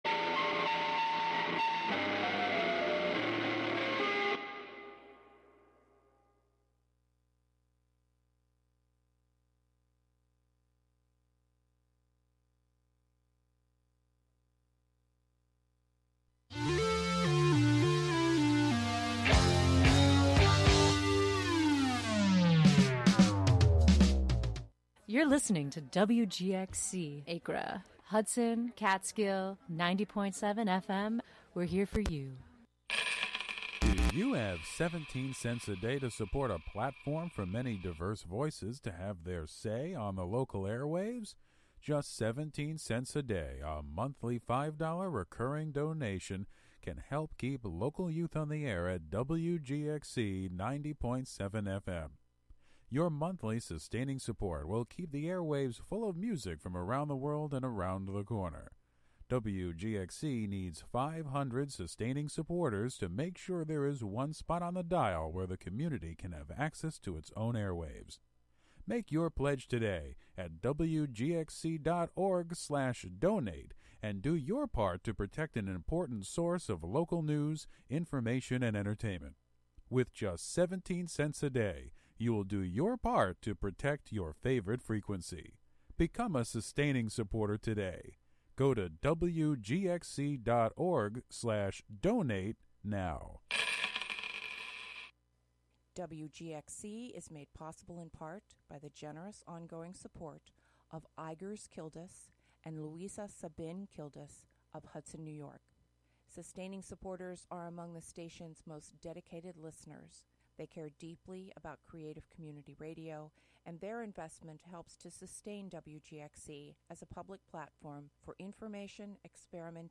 Live from Worthy Sound Works in Catskill, a variety show featuring stand-up comedy, music, discussion, and more
The varying panel of talented guests includes writers, actors, musicians, and other specialists with general topics to share. Listeners will hear nice stories, persistent flashbacks, attraction, avoidance, and the quest to make it right, whatever that may be.